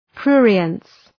Προφορά
{‘prʋrıəns}
prurience.mp3